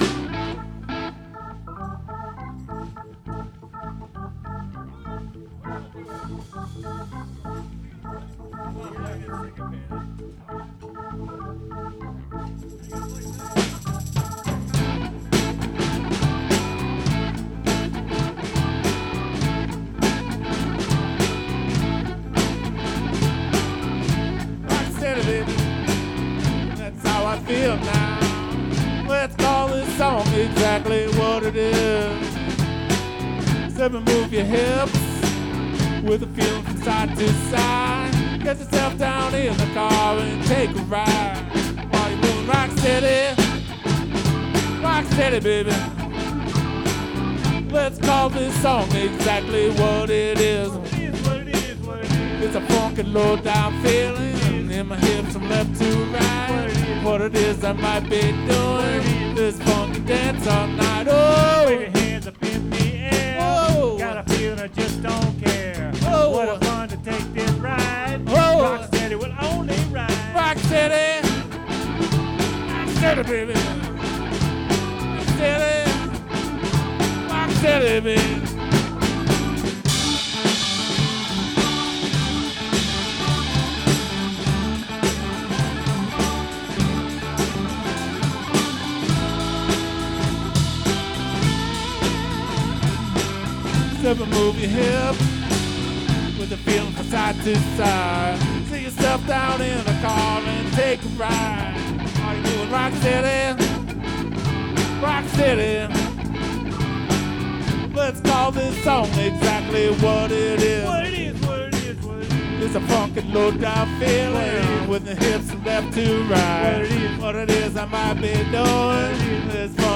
Am